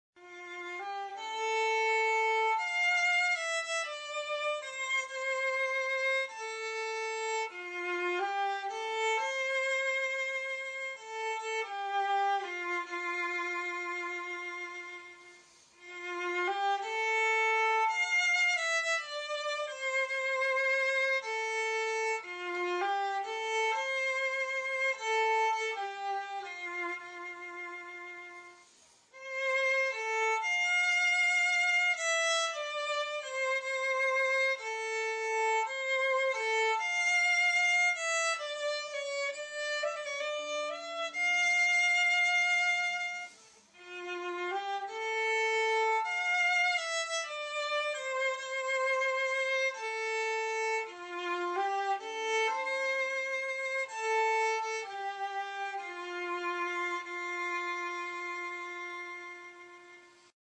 thelastroseofsummer-fiddleloop-1.mp3